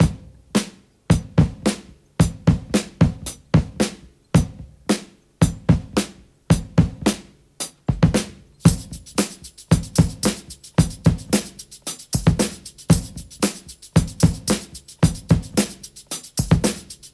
• 112 Bpm Drum Groove E Key.wav
Free drum loop sample - kick tuned to the E note.
112-bpm-drum-groove-e-key-d7y.wav